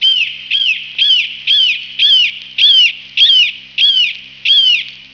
gaviao1.wav